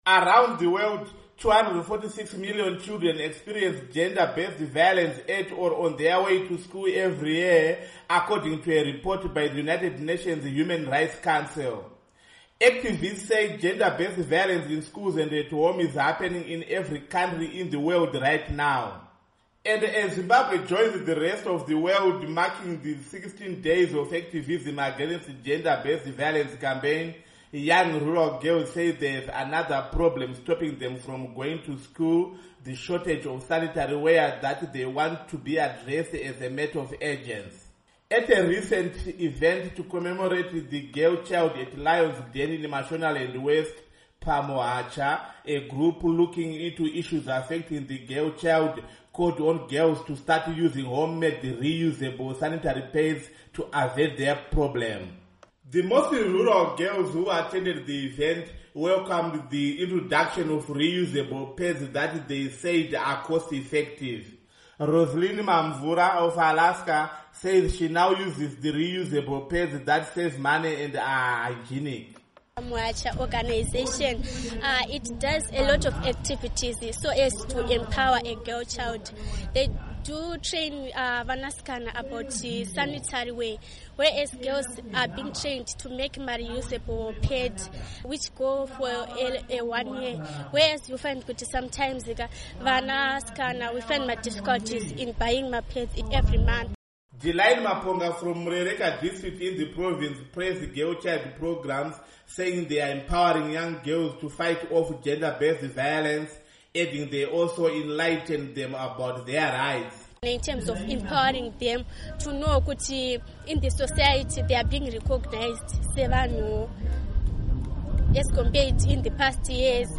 Report on Gender Activism